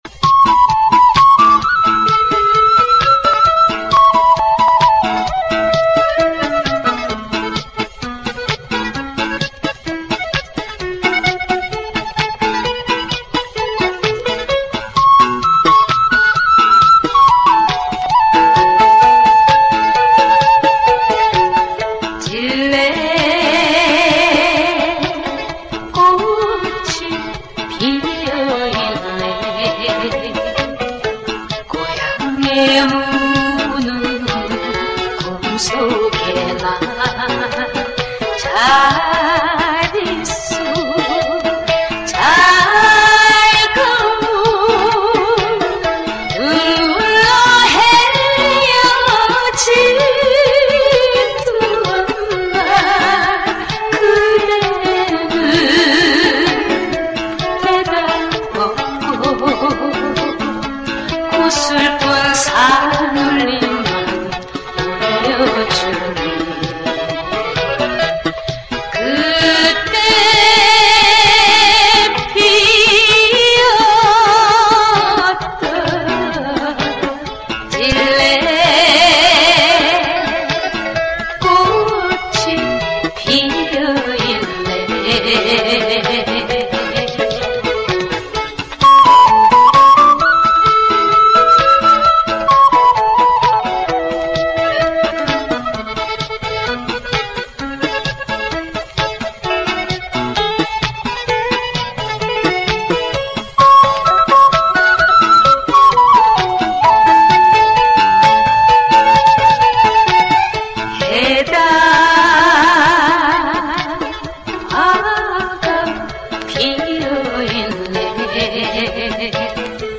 ※ 이 음반 경매로 구입 복각 하였습니다.